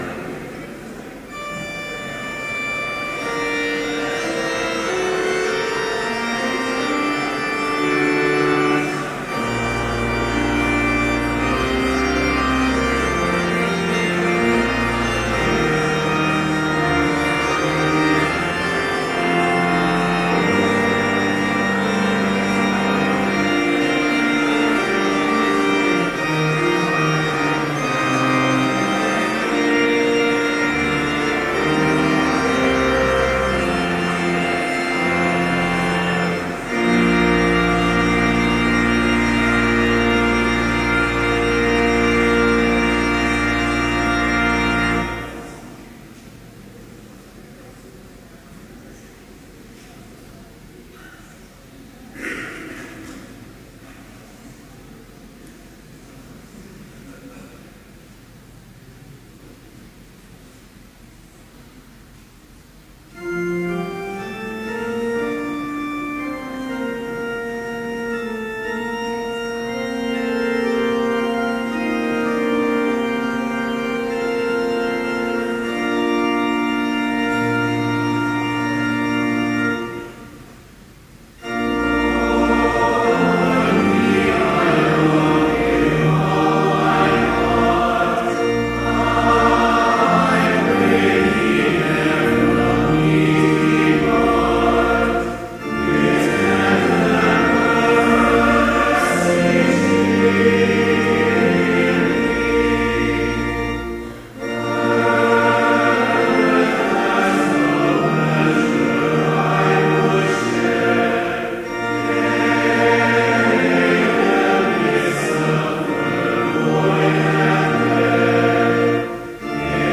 Complete service audio for Chapel - September 10, 2013